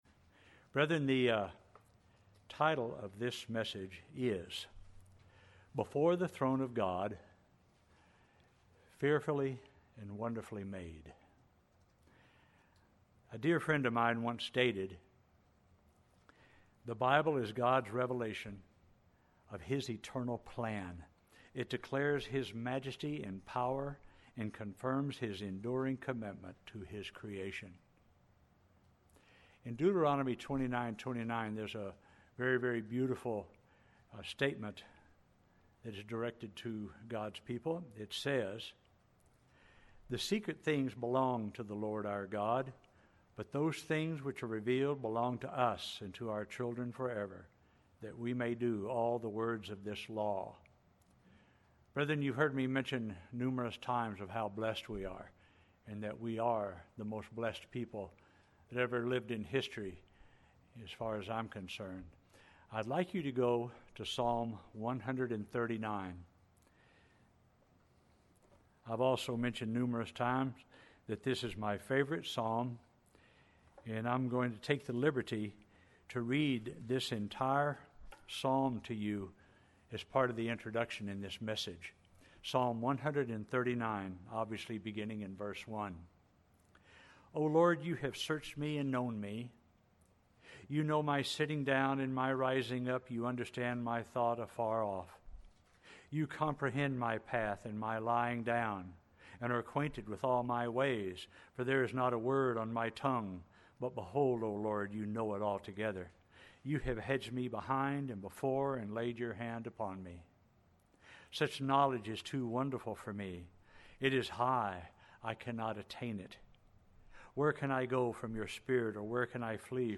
Sermons
Given in Oklahoma City, OK Salina, KS Wichita, KS